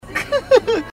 Laugh 11